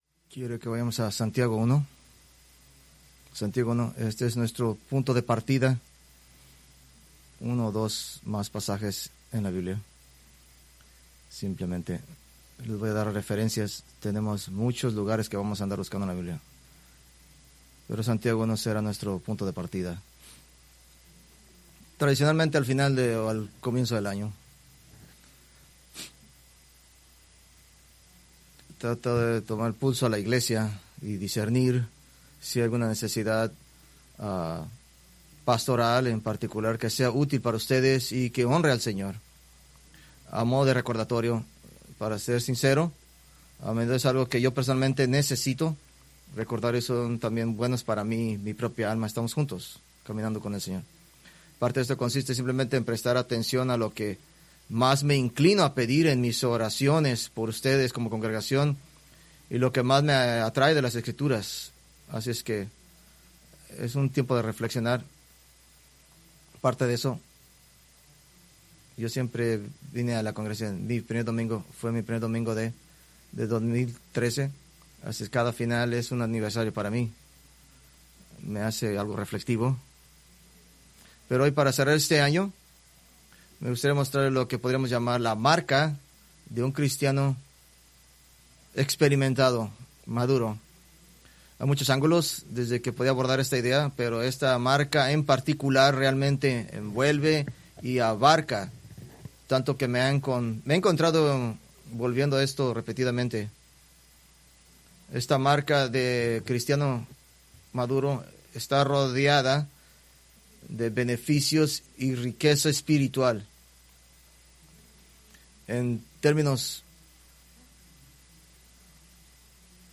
Preached December 28, 2025 from Escrituras seleccionadas